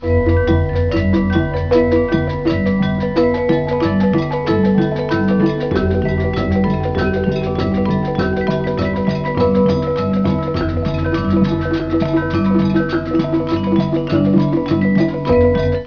Java, métal   (342 Kb)
Un gamelan est un ensemble instrumental traditionnel indonésien composé principalement de percussions : gongs, métallophones, xylophones, tambours, cymbales, flûtes.